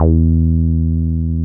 78.09 BASS.wav